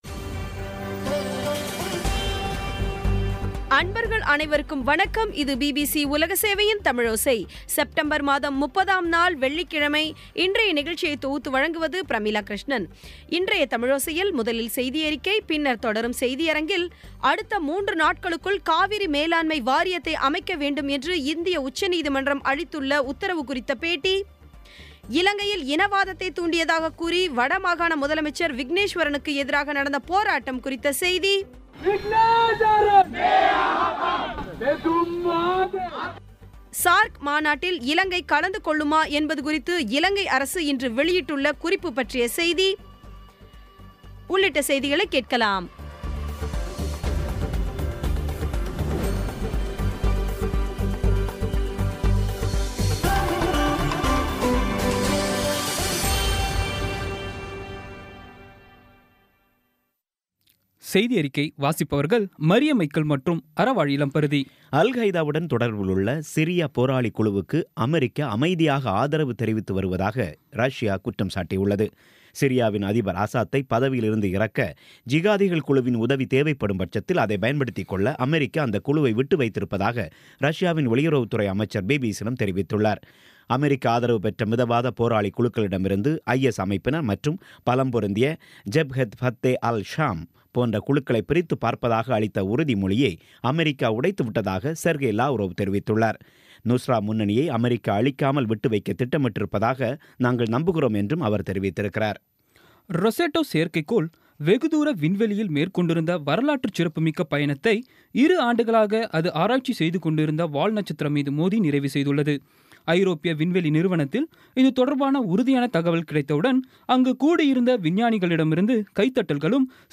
இன்றைய தமிழோசையில், முதலில் செய்தியறிக்கை, பின்னர் தொடரும் செய்தியரங்கத்தில், அடுத்த மூன்று நாட்களுக்குள் காவிரி மேலாண்மை வாரியத்தை அமைக்க வேண்டும் என்று இந்திய உச்சநீதிமன்றம் உத்தரவு குறித்த பேட்டி இலங்கையில் இனவாதத்தை தூண்டியதாகக் கூறி வடமாகாண முதலமைச்சர் விக்னேஸ்வரனுக்கு எதிராக நடந்த போராட்டம் குறித்த செய்திசார்க் மாநாட்டில் இலங்கை கலந்து கொள்ளவது குறித்து இலங்கை அரசு வெளியிட்ட குறிப்பு பற்றிய செய்தி உள்ளிட்ட செய்திகளைக் கேட்கலாம்